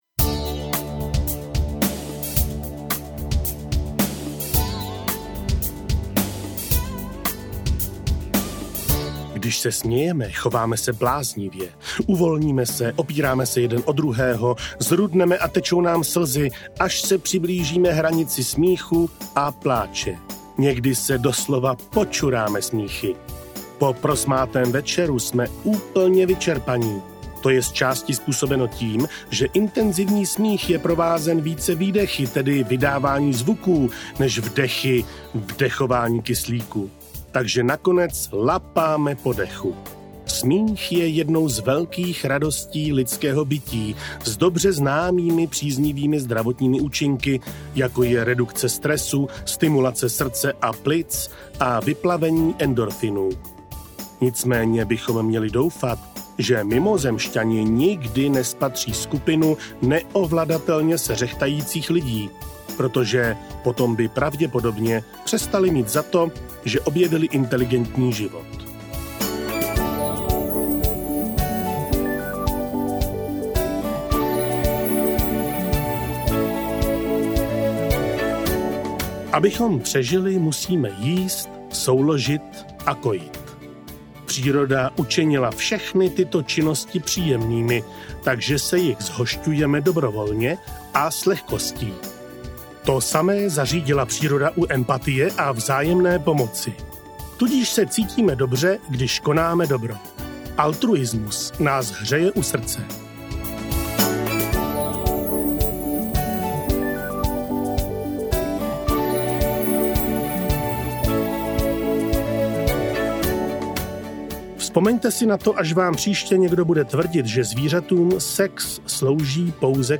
Mámino poslední objetí audiokniha
Ukázka z knihy